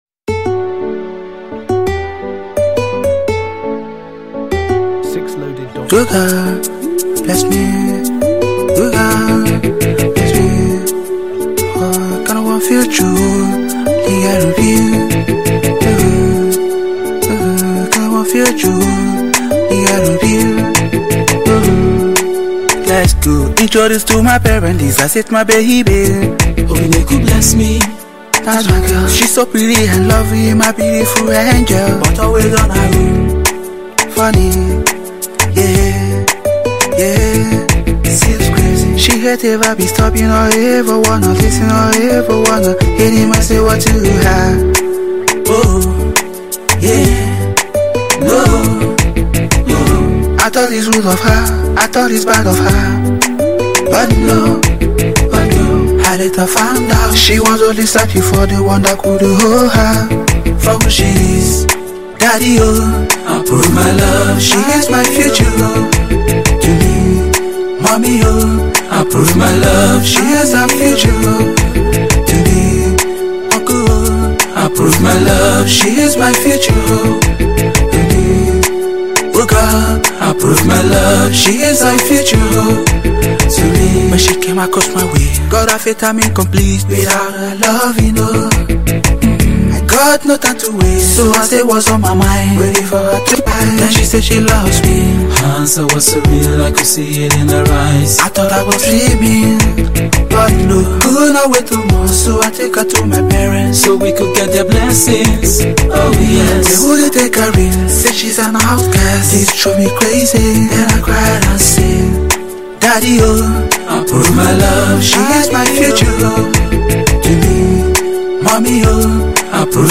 Nigerian singer